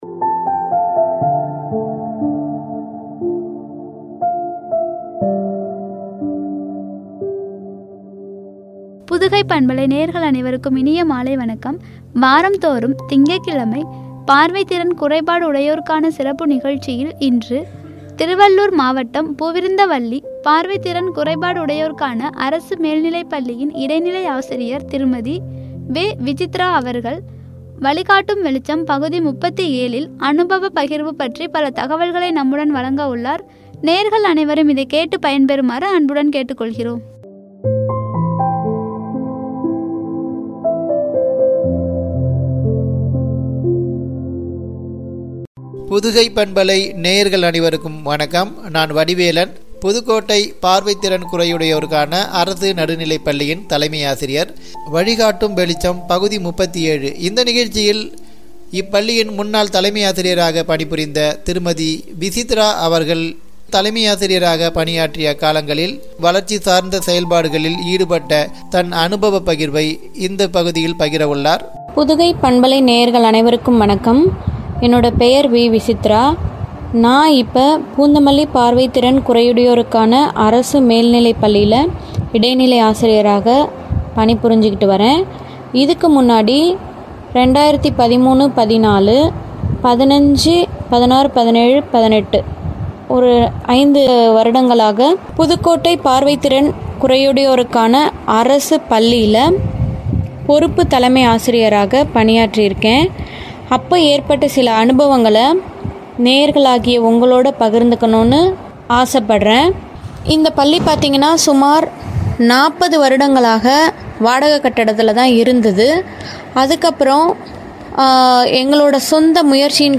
(அனுபவப் பகிர்வு)